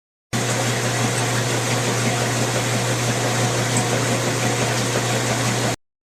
Washing Machine Scrub Cycle
SFX
Washing Machine Scrub Cycle.mp3